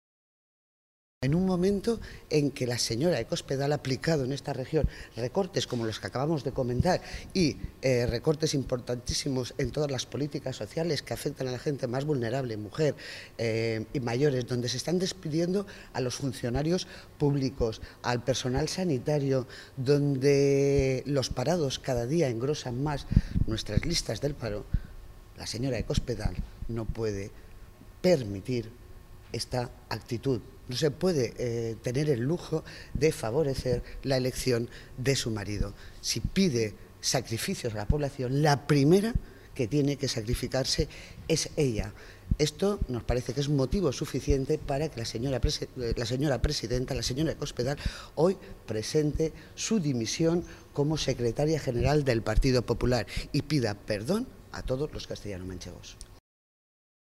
Mercedes Giner, diputada Regional del PSOE de Castilla-La Mancha
Cortes de audio de la rueda de prensa